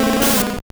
Cri d'Ortide dans Pokémon Or et Argent.